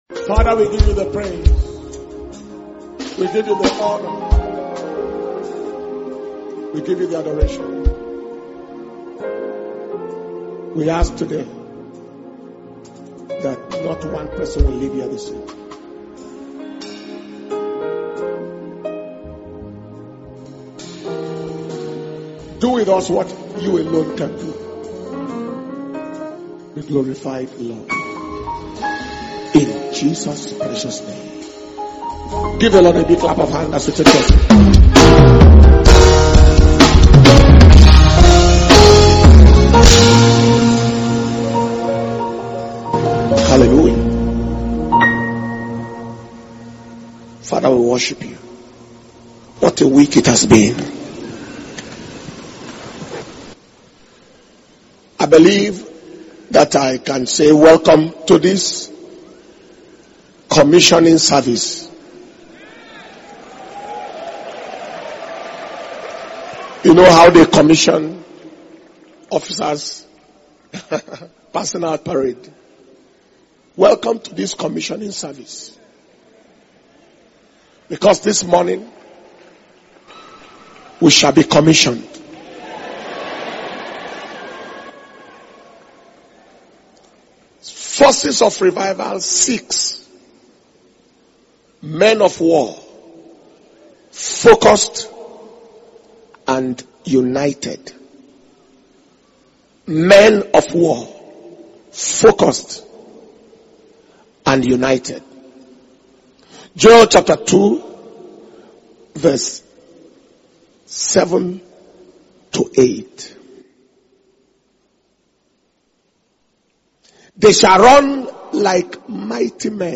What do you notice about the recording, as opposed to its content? International Flaming Fire Conference 2023 – Day 5 Evening Session